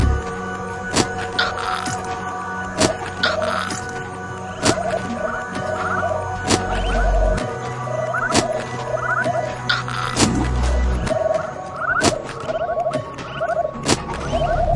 Triphop /舞蹈/拍/嘻哈/毛刺跳/缓拍/寒意
标签： 寒意 旅行 电子 舞蹈 looppack 样品 毛刺 节奏 节拍 低音鼓 实验 器乐
声道立体声